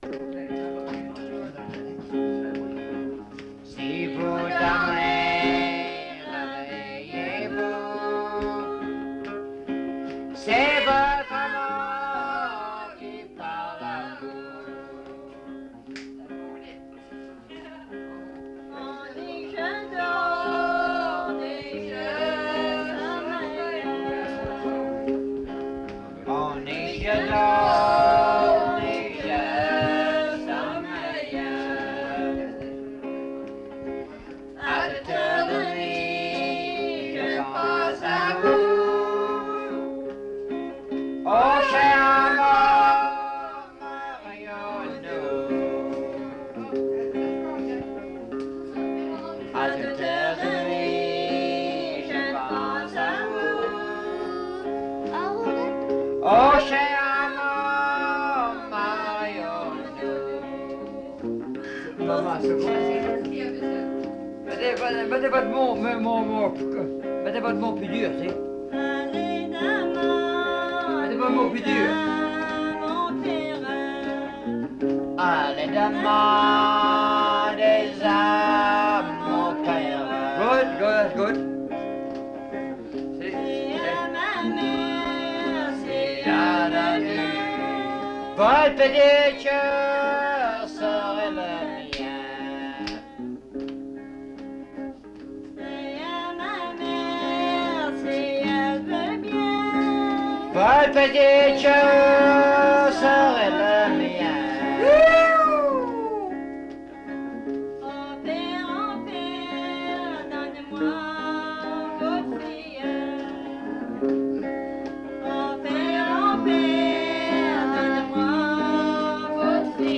L'Anse-aux-Canards
Avec guitare